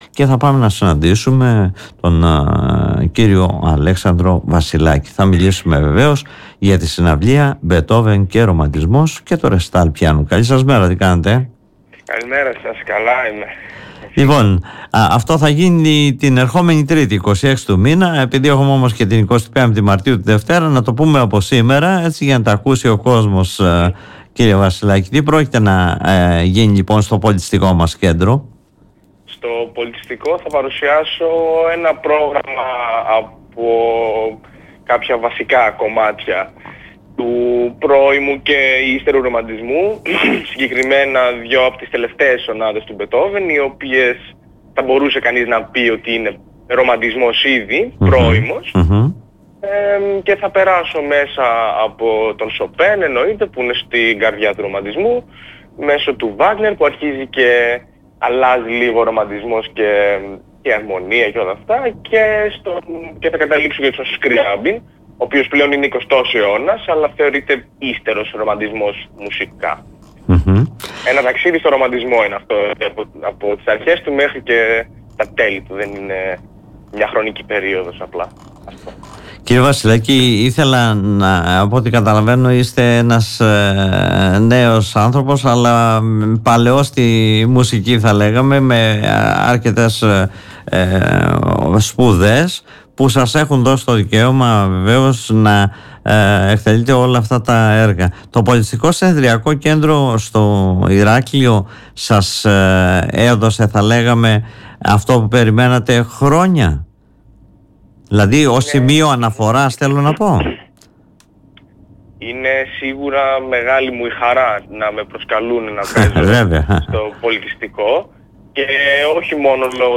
μιλώντας στην εκπομπή “Δημοσίως”